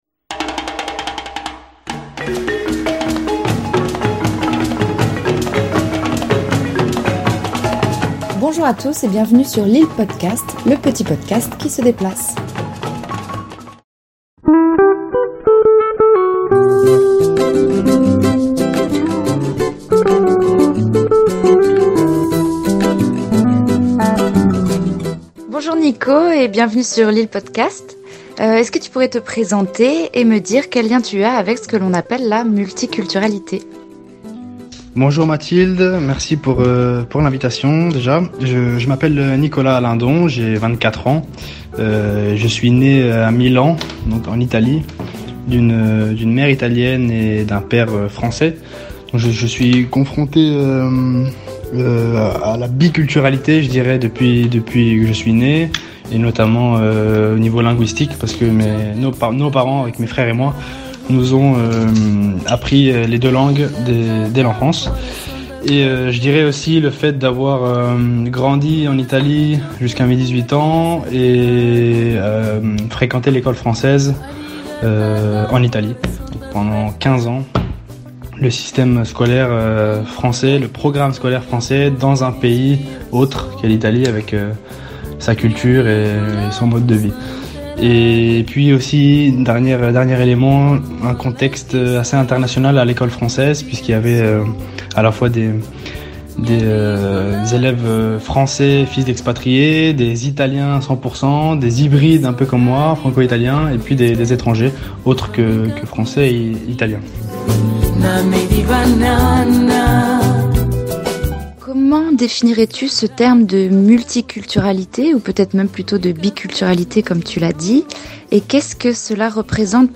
Itw et réalisation